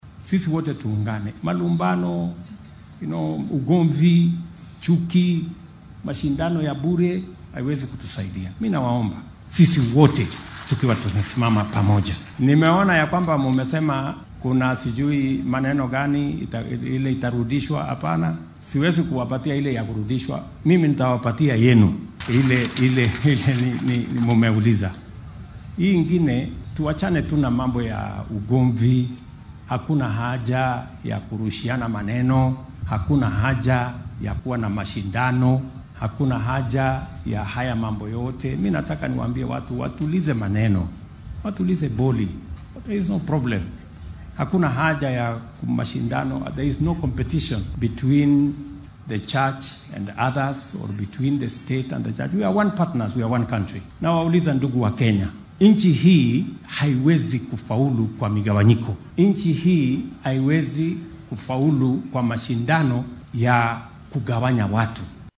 Xilli uu ku sugnaa ismaamulka Kajiado ayuu sheegay in uunan jirin wax tartan ah oo ka dhexeeya dowladda iyo kaniisadaha.
William-Ruto-Baaqa-midnimada.mp3